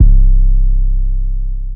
BTTRF - 808 .wav